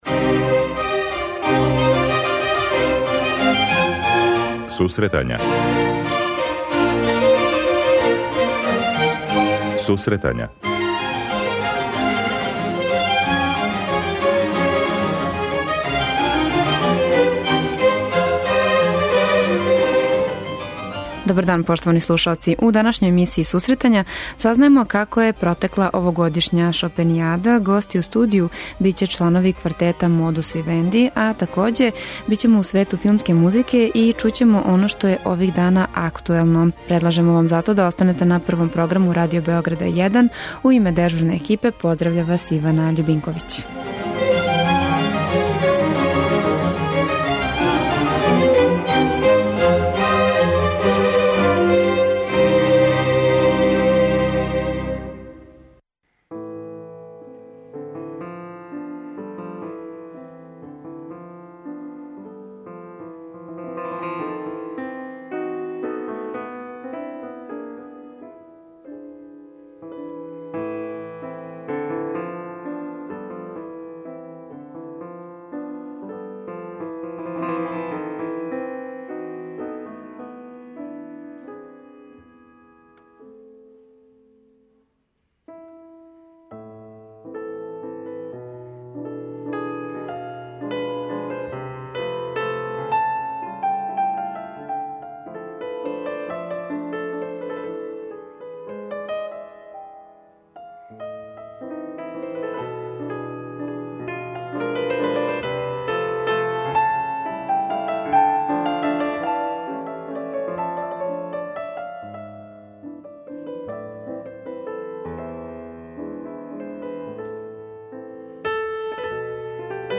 Данас говоримо о завршеној Шопенијади, чућемо новости са Факултета музичке уметности, а гости у студију биће чланови квартета Модус Вивенди. У сусрет сутрашњем концерту Београдске филхармоније на Коларцу, слушаћемо и филмску музику.